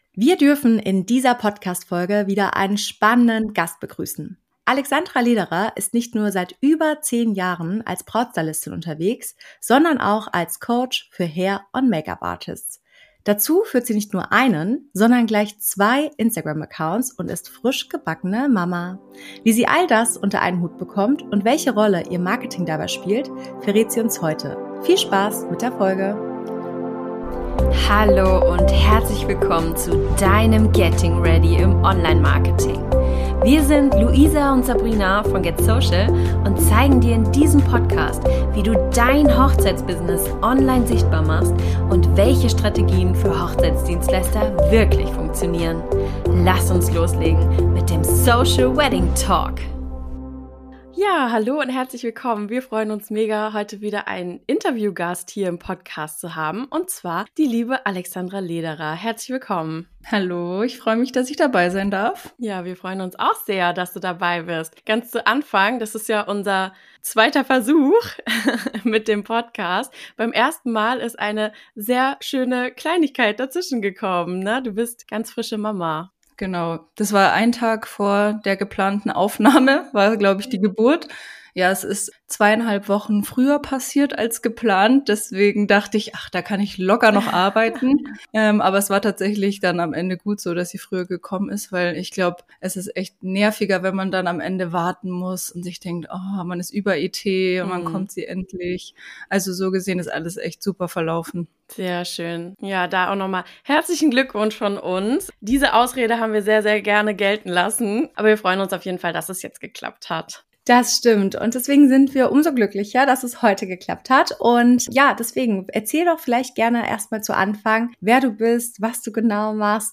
In dieser spannenden Interviewfolge sprechen wir mit